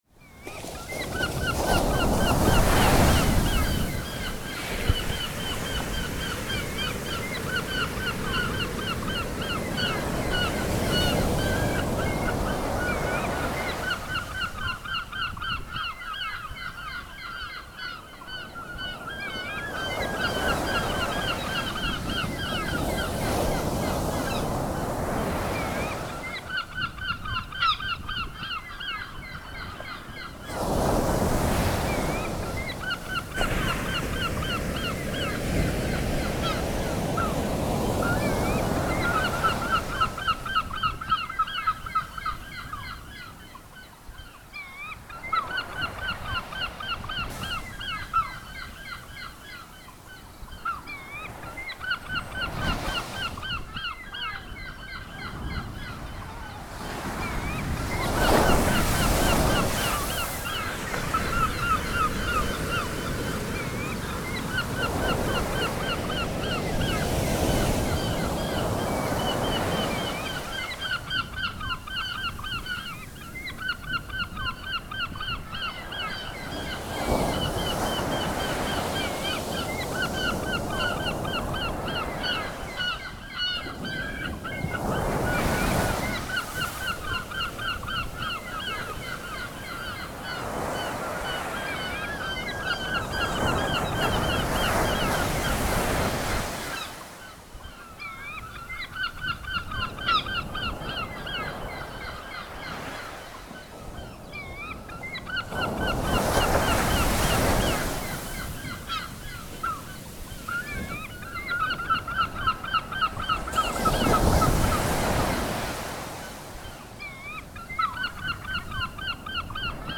L’istallazione ha voluto raccontare l’experience a bordo del main saloon dell’ammiraglia del Cantiere, in uno scenario di luce e suoni marini, dagli effetti di onde del mare e voce dei gabbiani.
AudioSea.mp3